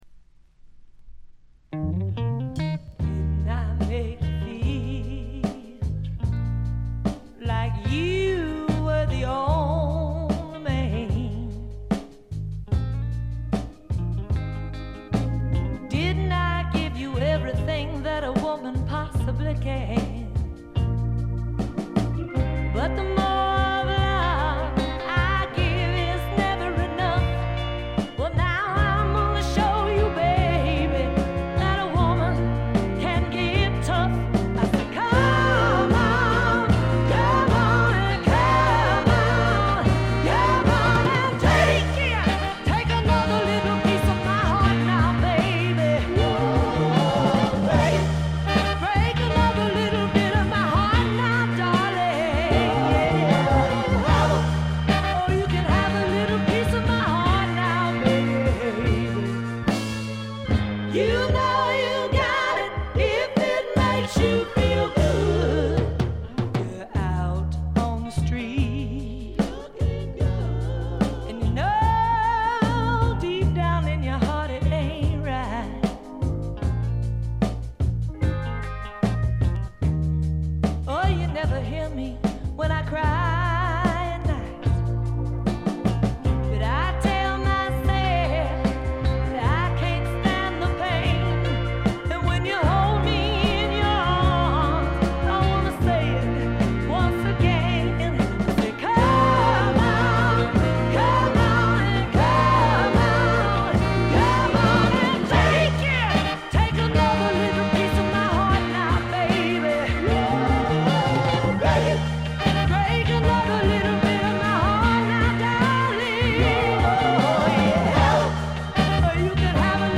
ほとんどノイズ感無し。
南部ソウル完璧な一枚。
試聴曲は現品からの取り込み音源です。